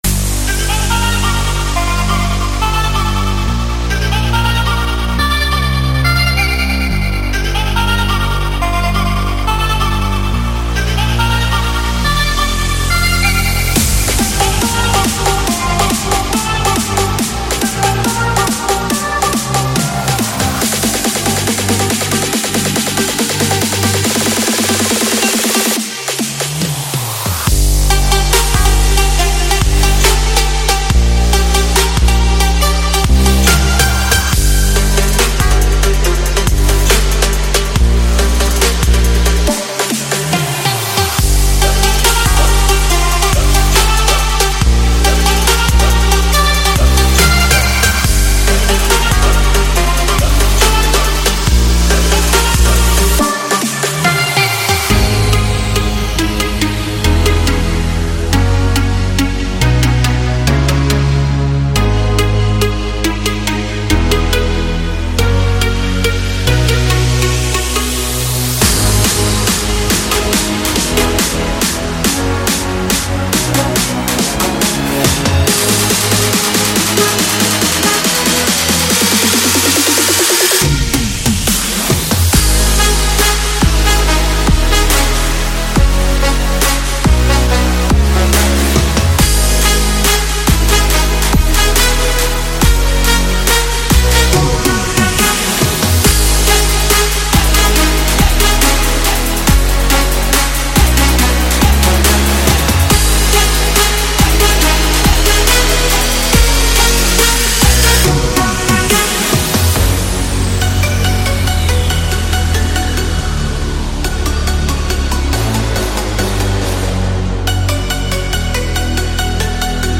– 3 Drop Brass WAV Stems
-8 Drop 808WAV Stems
– 8 Demo Mixdown WAVs